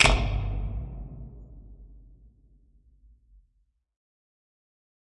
На этой странице представлена коллекция звуков рубильника — от четких металлических щелчков до глухих переключений.
Звук опущенной ручки нерабочего рубильника